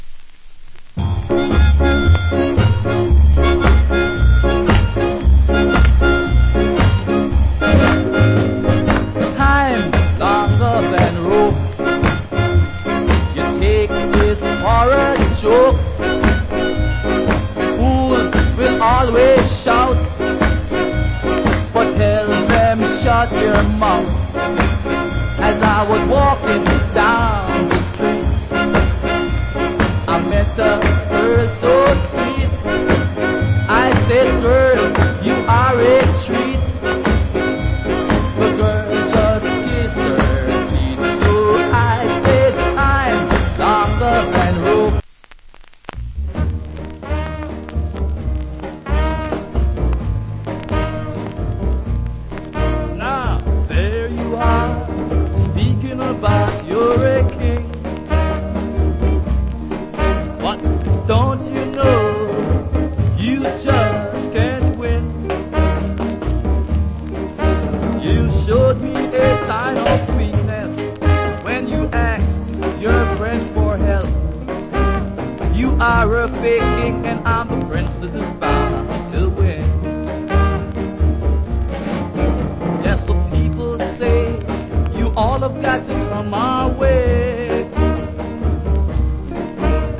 Ska Vocal.